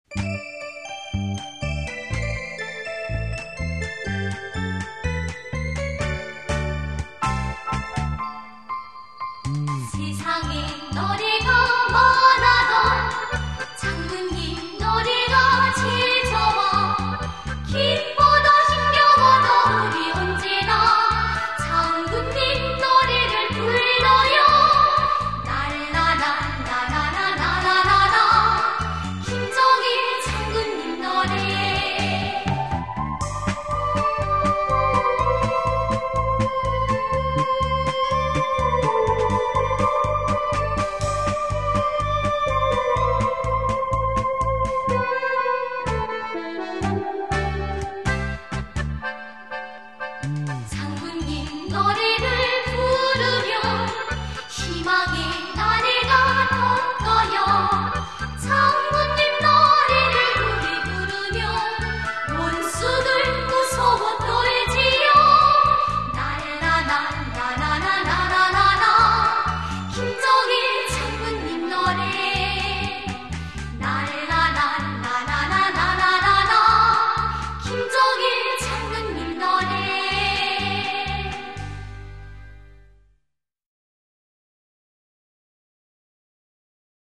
Korean Children's music